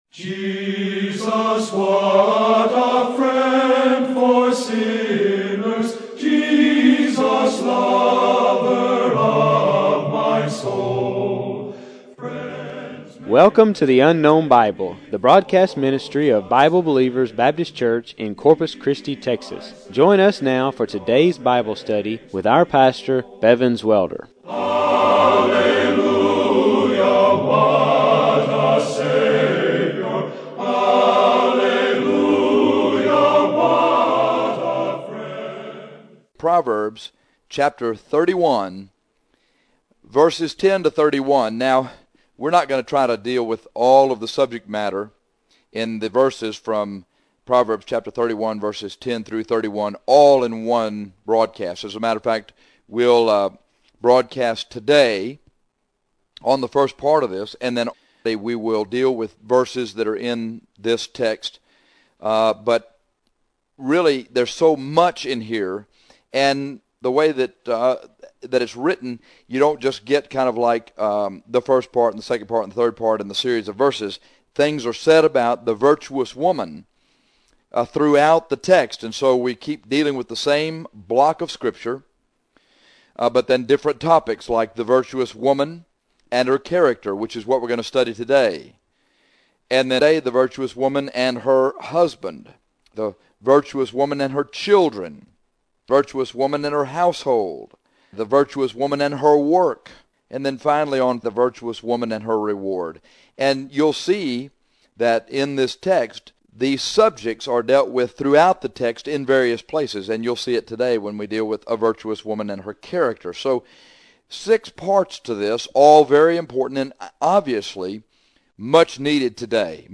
This lesson is about a virtuous woman and her character.